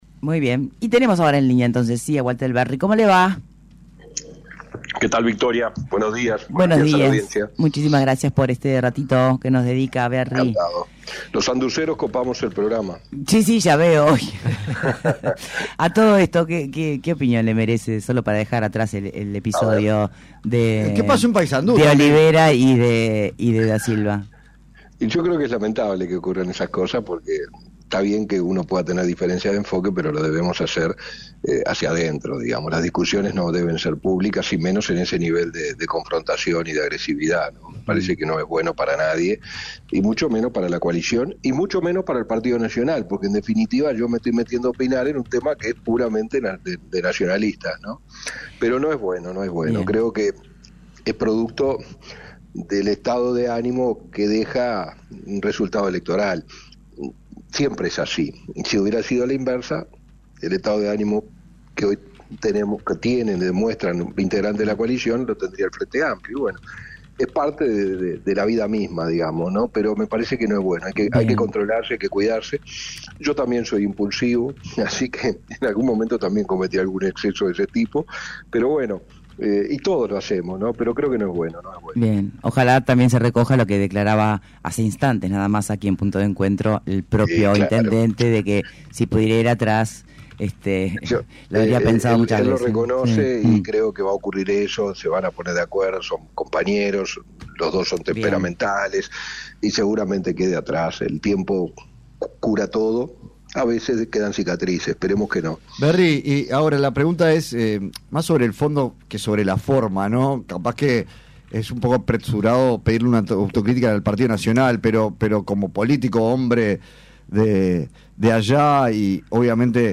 Entrevista a Walter Verri